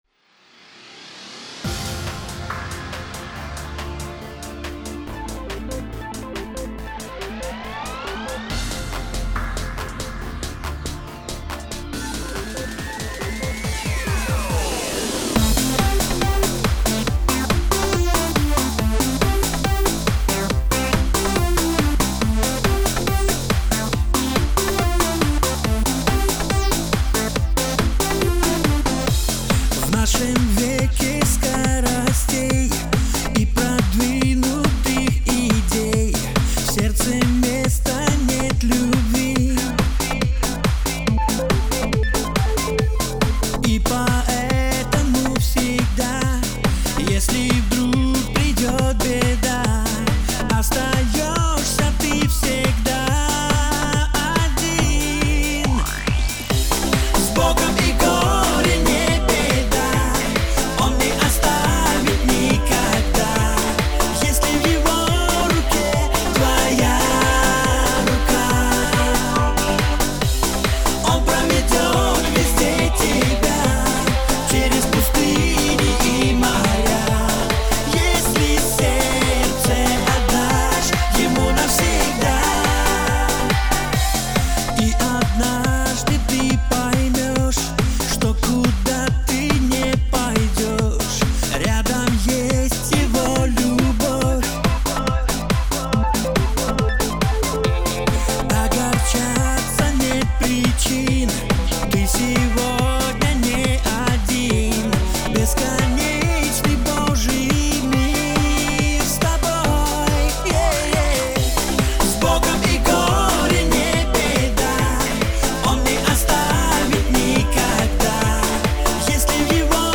4772 просмотра 3849 прослушиваний 534 скачивания BPM: 140